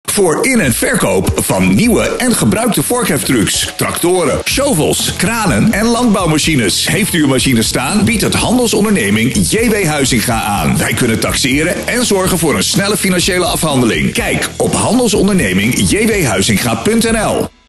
Onze reclamespot is te beluisteren op Joy Radio, Radio NL, Tukkers FM en Waterstad FM.